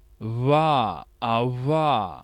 A multi-speaker model for Gujarati based on the CMU Indic dataset.
47 ʋ consonant approximant labio-dental voiced [
labiodental_approximant.wav